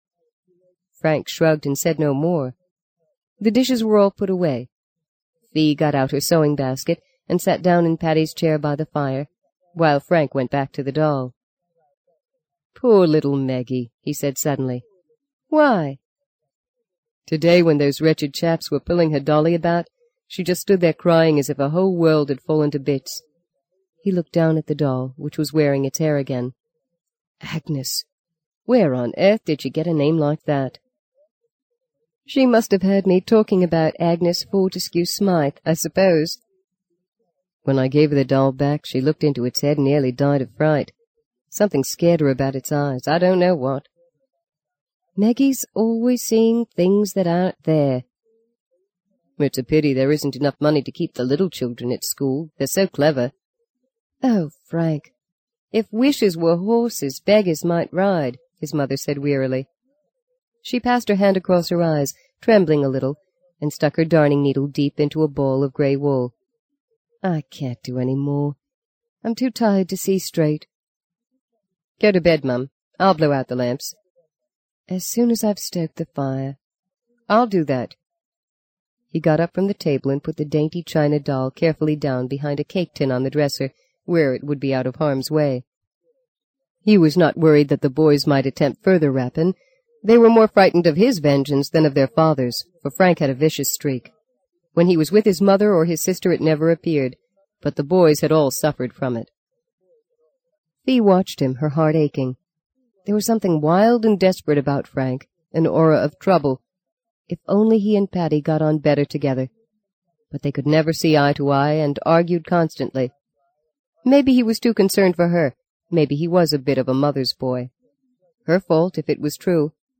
在线英语听力室【荆棘鸟】第一章 09的听力文件下载,荆棘鸟—双语有声读物—听力教程—英语听力—在线英语听力室